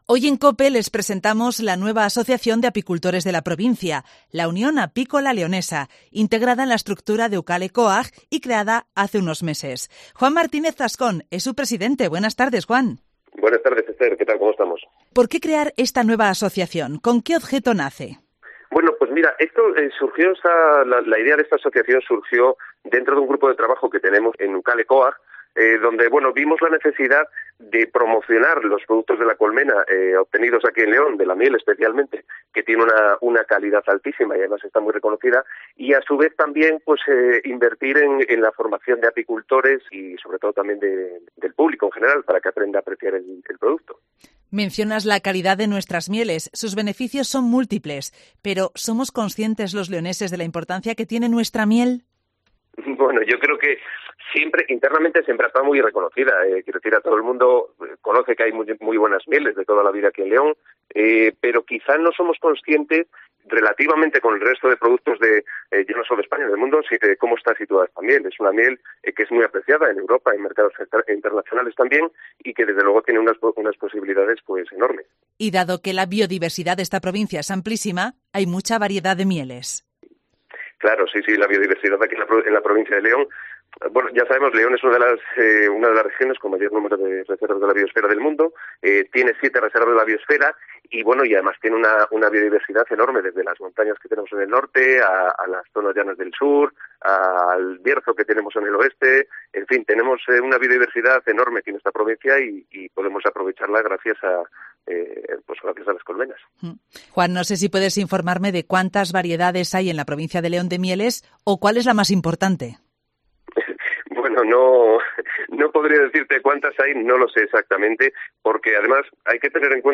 Hoy en Cope hablamos con